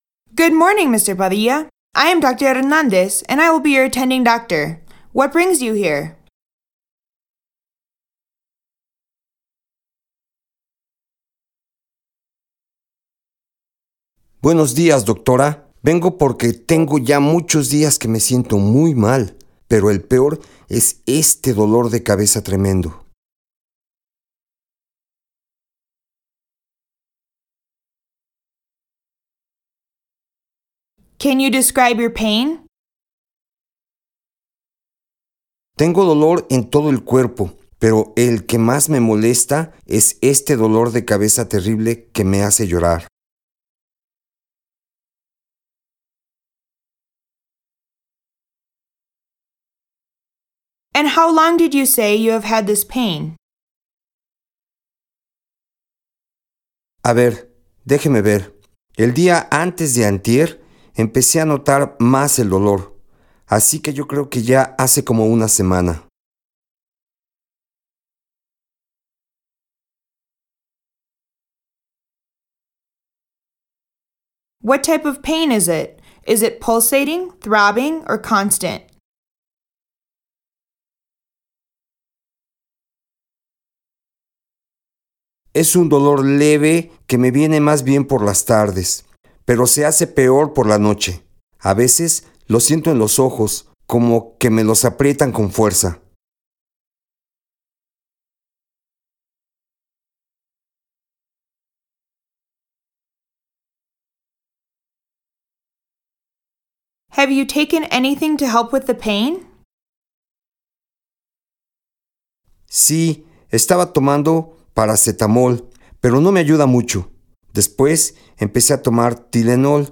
VCI-Practice-Dialogue-01-Typhoid-EN-SP.mp3